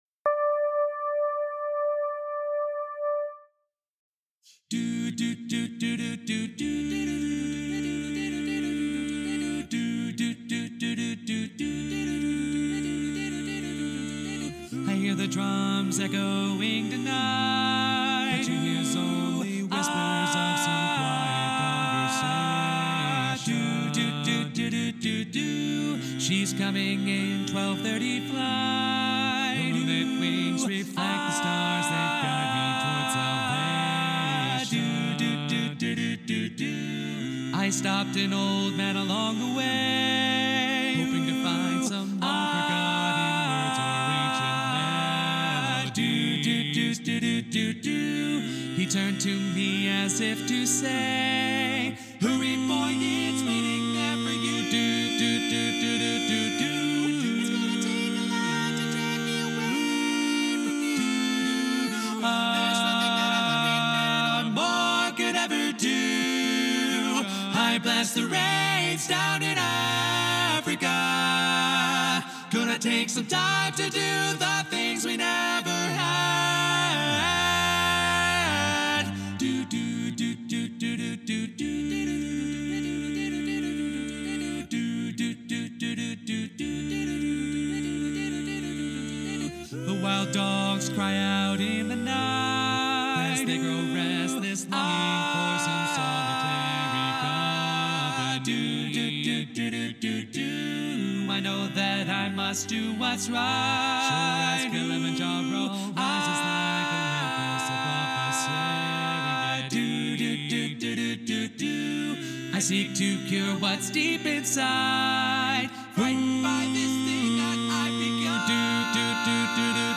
Up-tempo
B♭Major
Bass